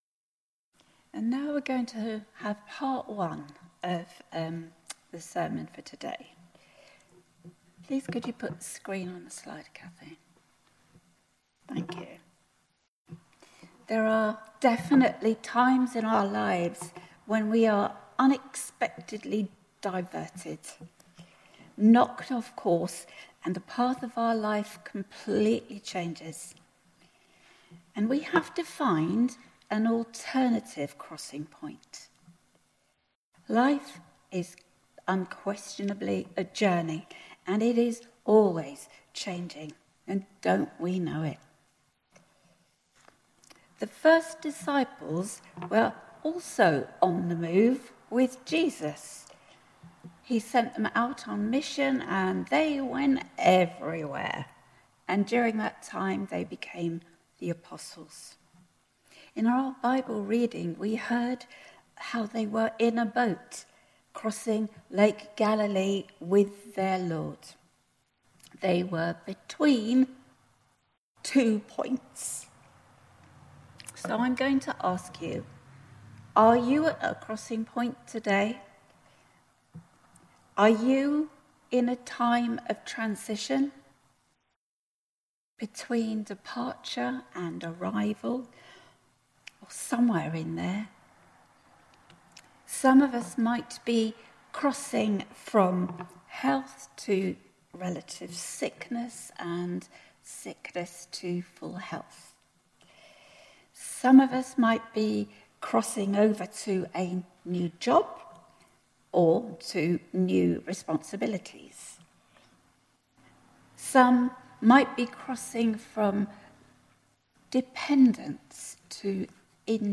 Passage: Mark 6:30-34, 53-56, Psalm 6 Service Type: Sunday Morning
07-18-sermon.mp3